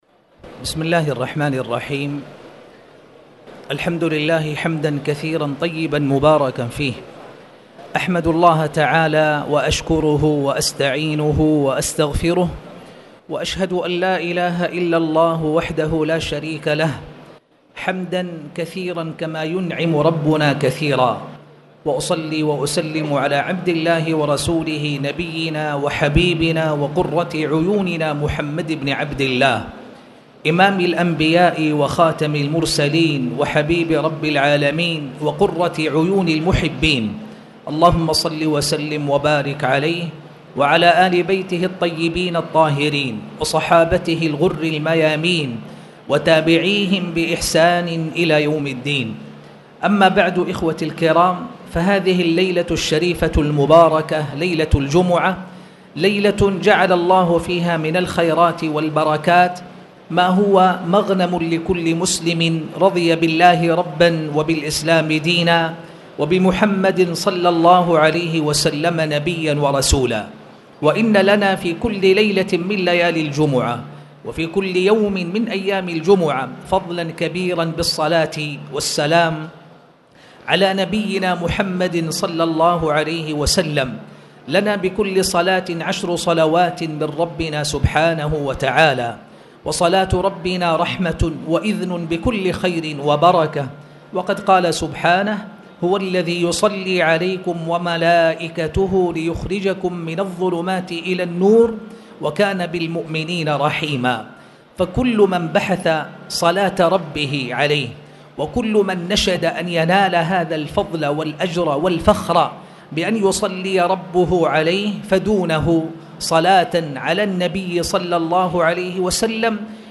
تاريخ النشر ٢٨ ربيع الثاني ١٤٣٨ هـ المكان: المسجد الحرام الشيخ